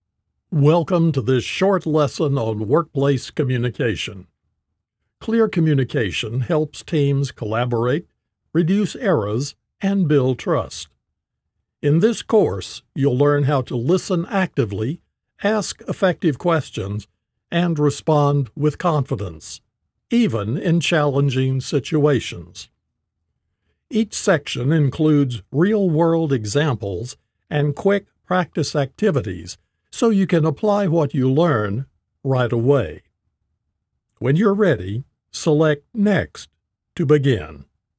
Male
Adult (30-50), Older Sound (50+)
I'm a full-time Professional American Voice Actor with a friendly and distinctive voice.
Short E-Learning Demo
All our voice actors have professional broadcast quality recording studios.